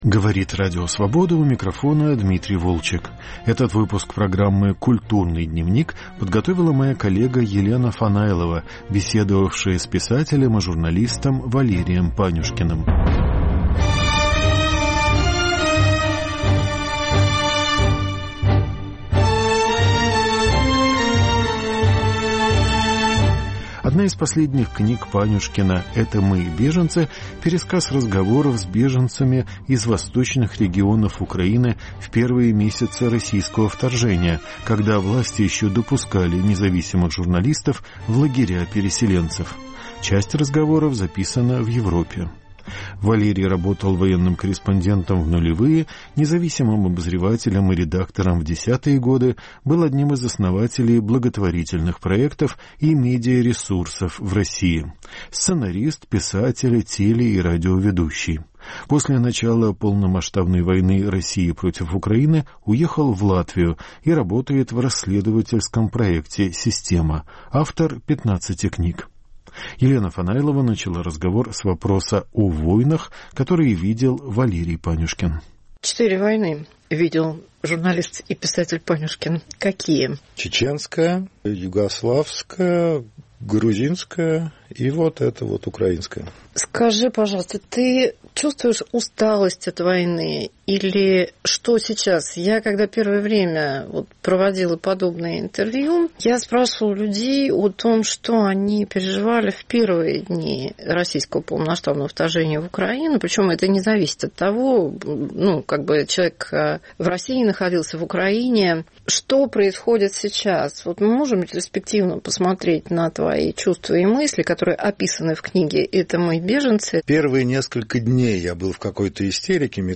Елена Фанайлова беседует с автором книги "Это мы, беженцы"